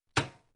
• 声道 立體聲 (2ch)